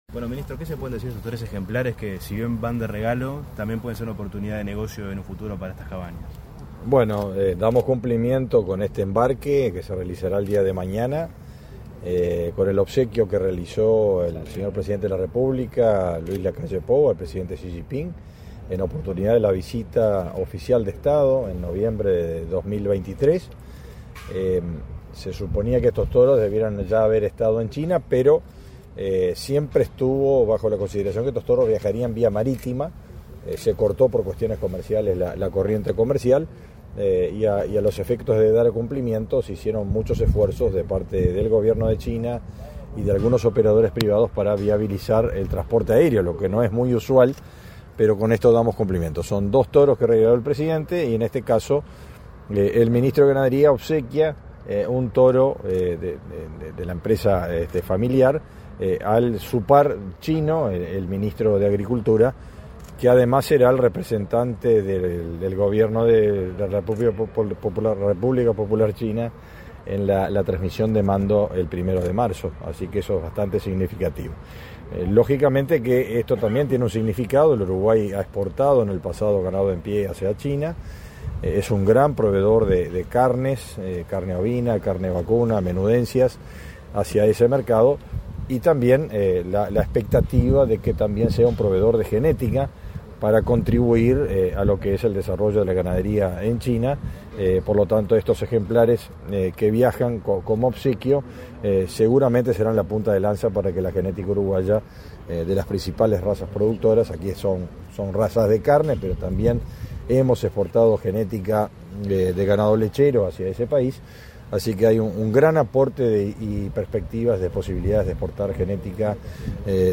Declaraciones a la prensa del titular del MGAP, Fernando Mattos
En la oportunidad, el titular del Ministerio de Ganadería, Agricultura y Pesca (MGAP), Fernando Mattos, realizó declaraciones a la prensa.